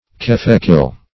[1913 Webster] The Collaborative International Dictionary of English v.0.48: Keffe-kil \Kef"fe-kil\, n. (Min.)